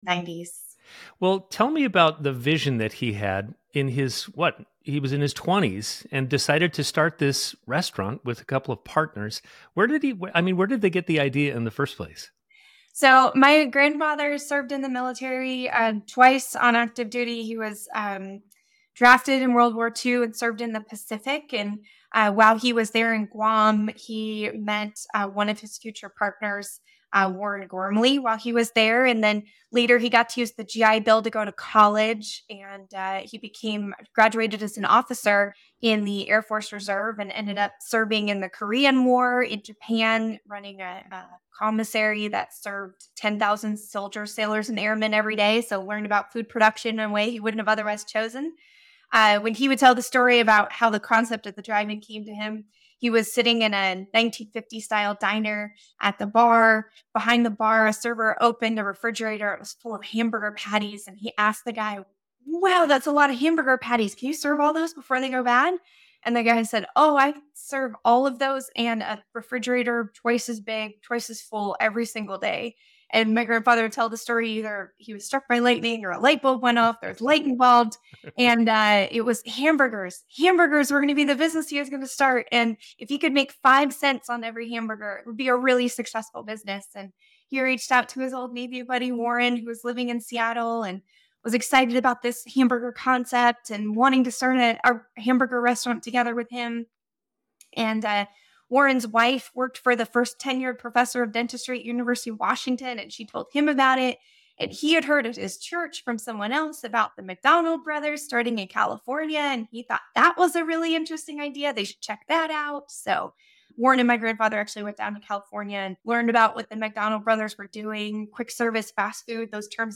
Guest: